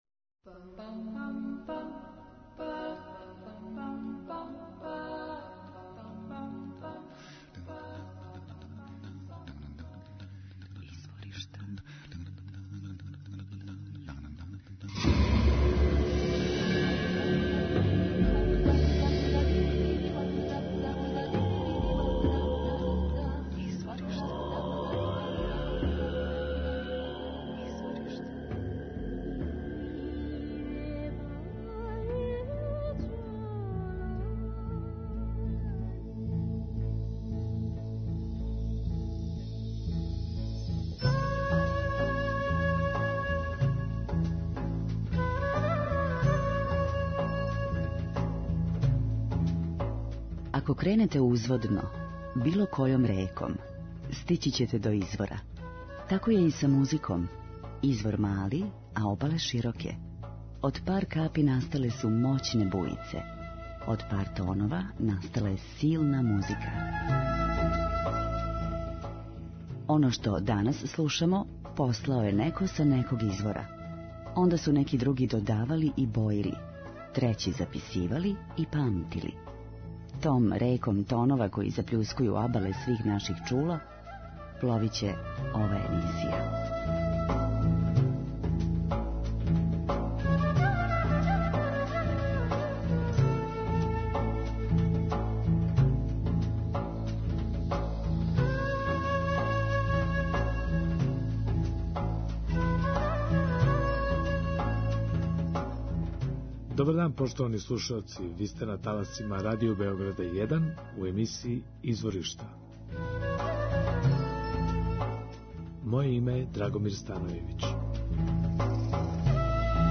Мароканска певачица